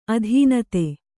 ♪ adhīnate